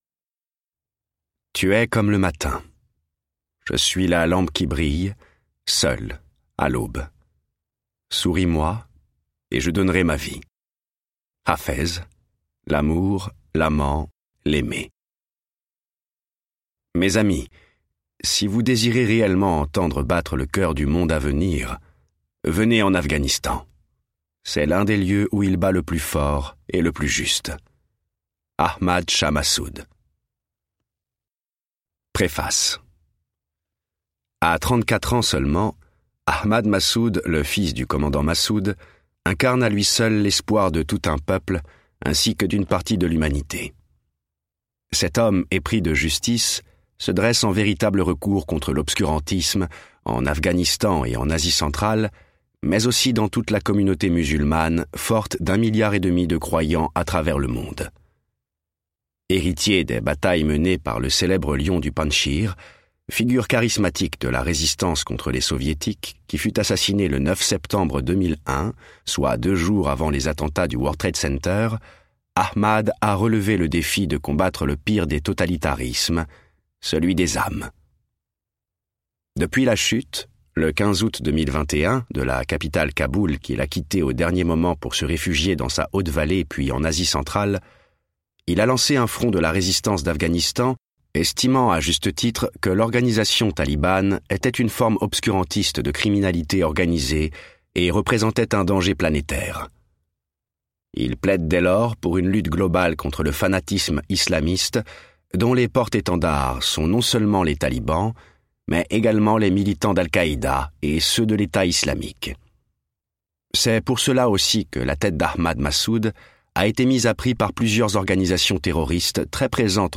Un livre audio essentiel et salutaire, une ode à la liberté.